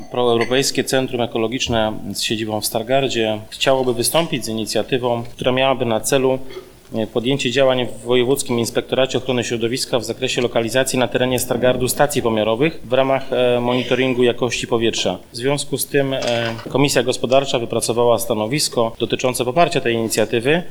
Rozpoczęła się 28. sesja Rady Miejskiej w Stargardzie.
– mówi radny Krzysztof Pakulski.